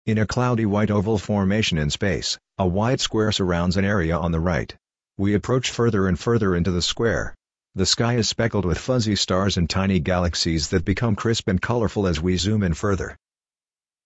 • Audio Description
Audio Description.mp3